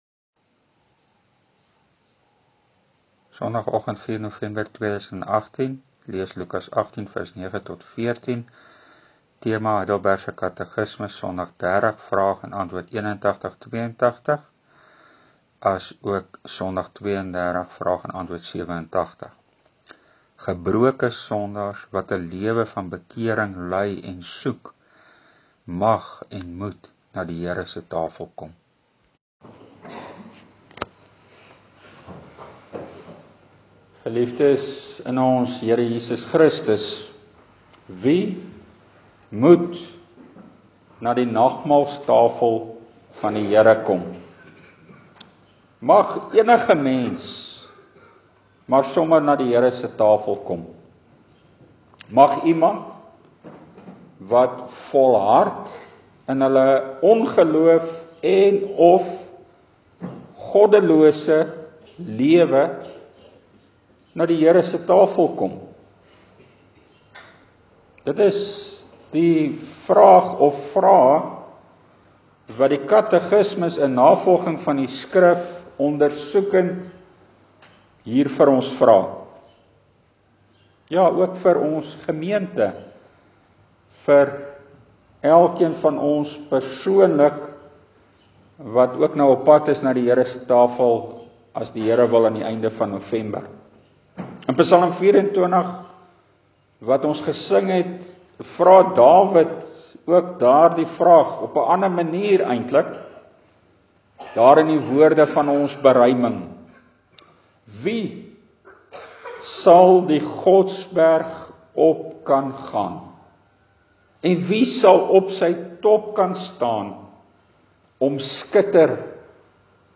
Preek: Wie mag of moet na die Here se tafel kom? (Lukas 18:13)